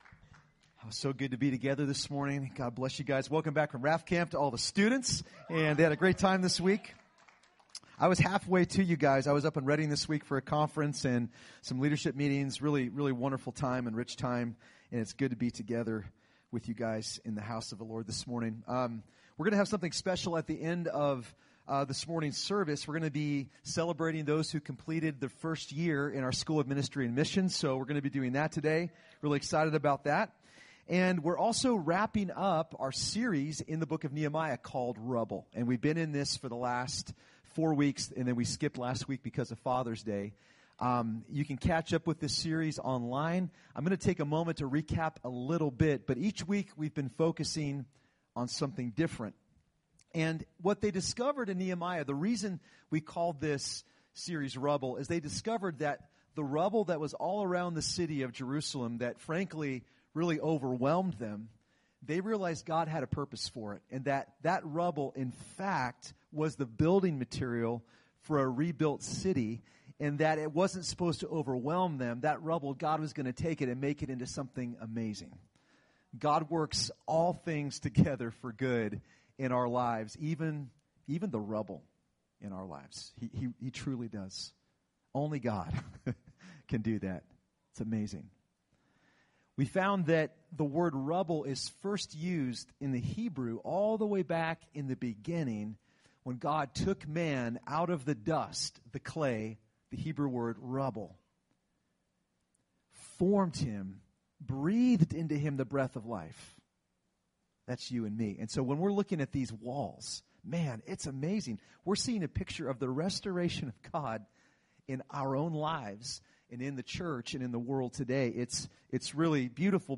Recorded at New Life Christian Center, Sunday, June 24, 2018 at 11 AM.